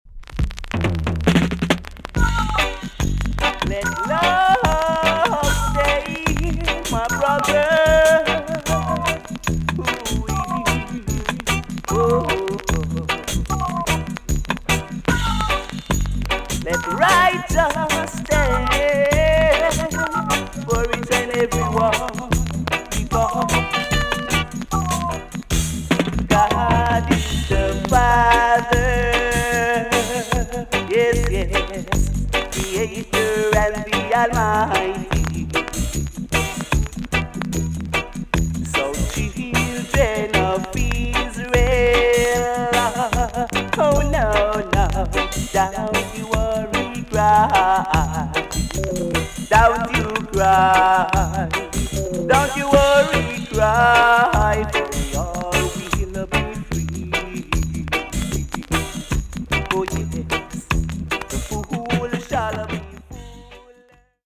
TOP >REGGAE & ROOTS
VG+ 少し軽いチリノイズが入ります。
1978 , RARE , NICE ROOTS TUNE!!